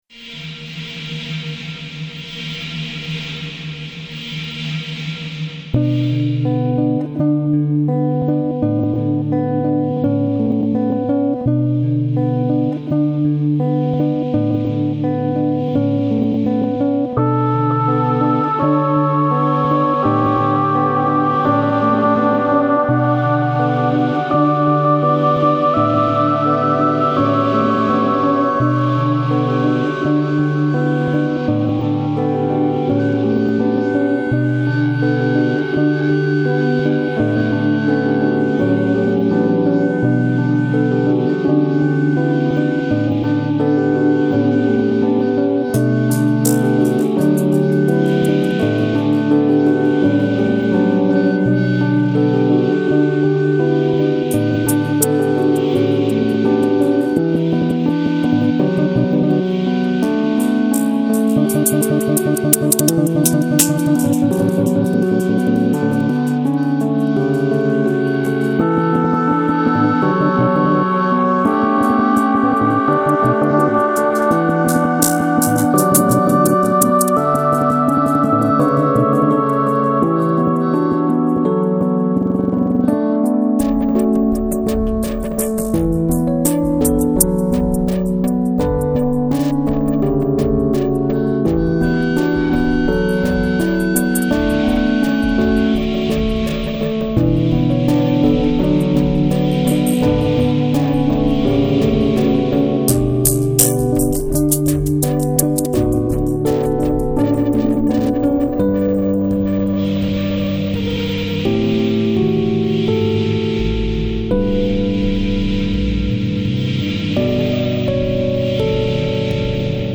version electronica